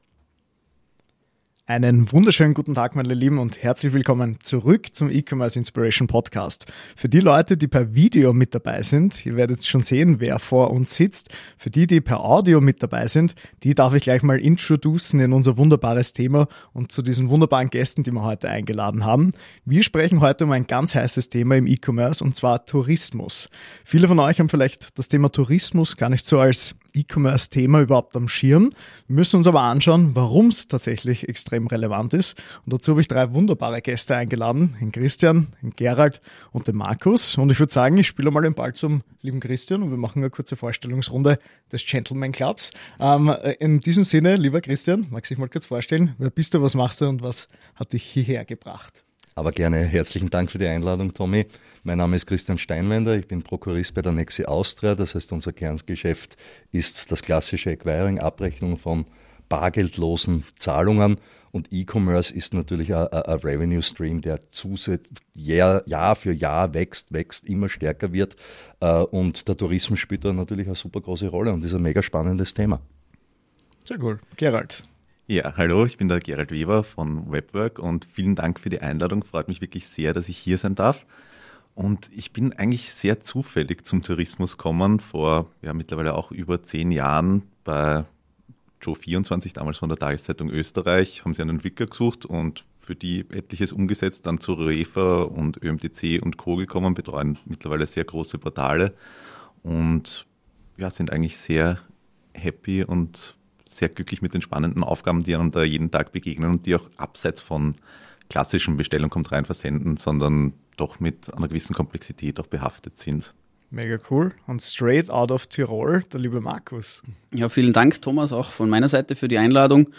#058: Roundtable: Wie läuft E-Commerce im Tourismus ab?